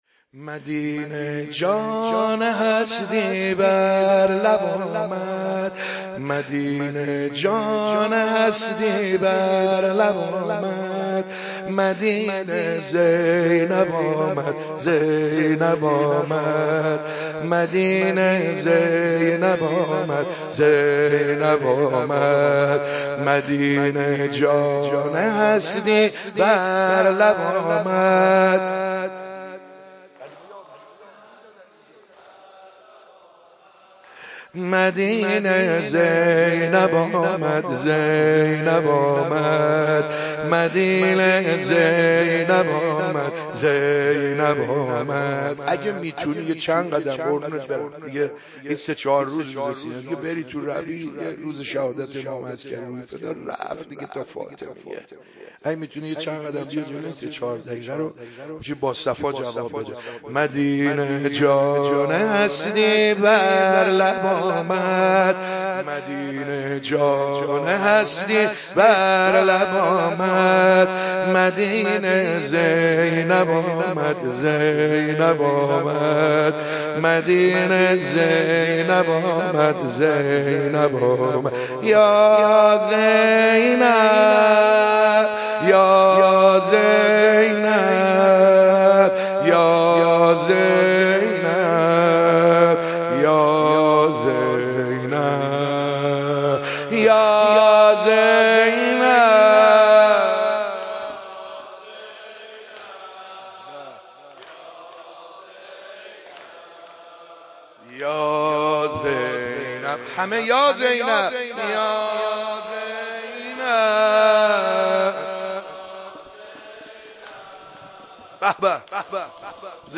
28صفر 96 - سینه زنی - حسینیه حق شناس